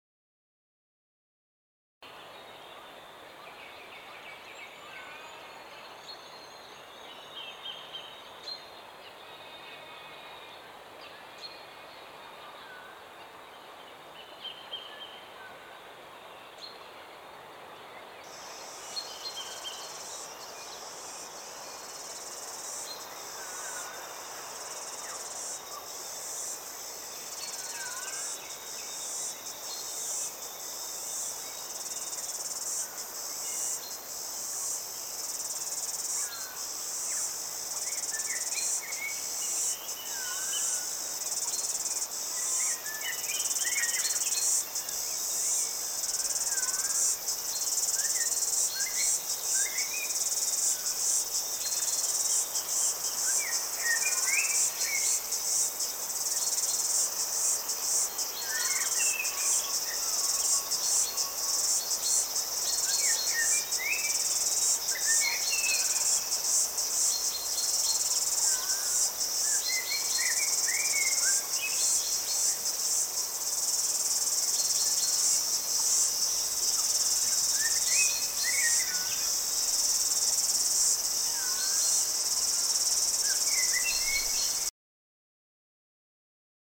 An album of Waiheke birdsong.
Recorded over a period of 30 years, the album includes an hour of birdsong, air, water, and insect sounds.
Cicadas
cicadas.mp3